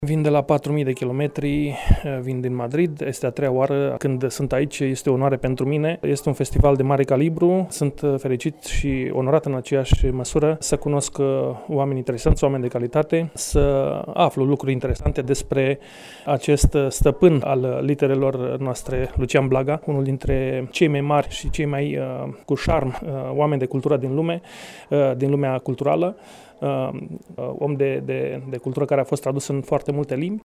A XVIII-a ediție a Festivalului Internațional ,,Lucian Blaga”, organizat de Societatea Scriitorilor Mureșeni și Editura Ardealul, a debutat, vineri, la Radio România Tg.Mureș.